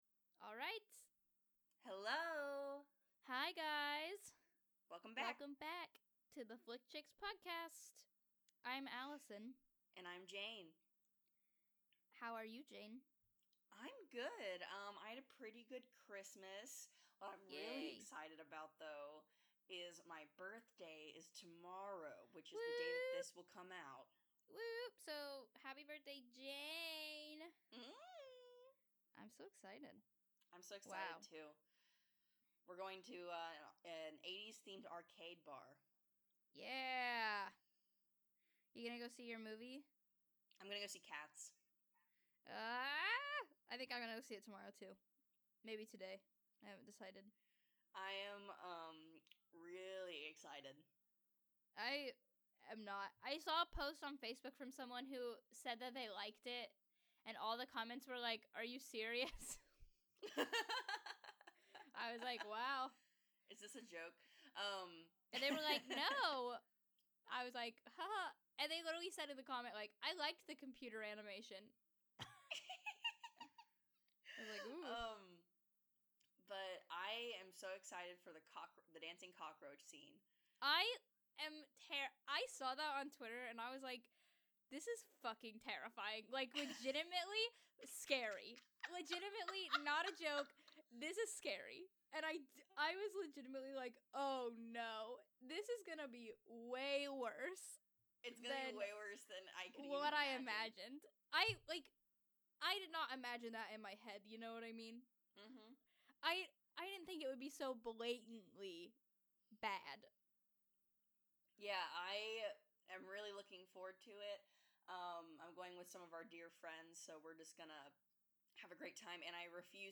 Thank you for continuing to support us, even though we haven't been delivering you the best quality.